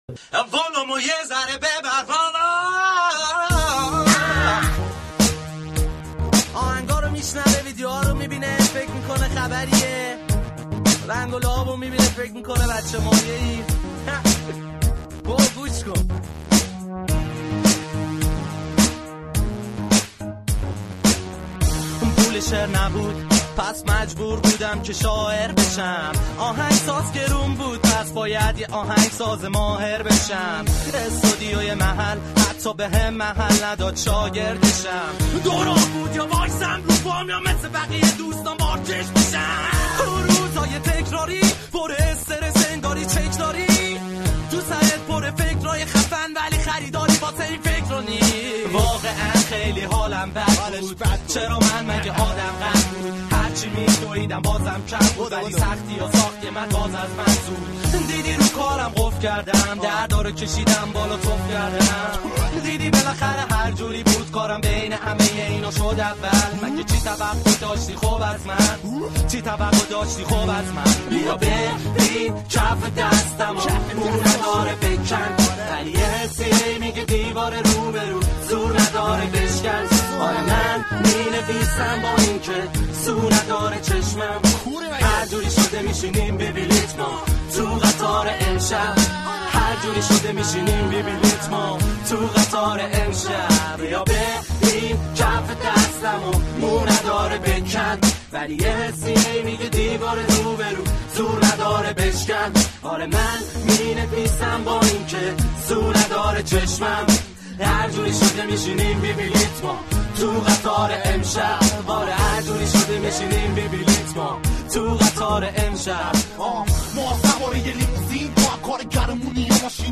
همه ی اخبار ها و حاشیه های رپ فارسی